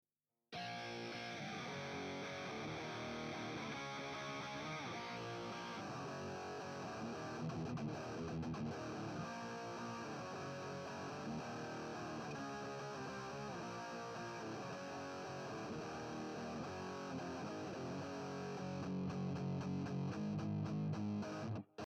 Ungewollter Flanger ähnlicher Effekt bei Gitarrenaufnahme!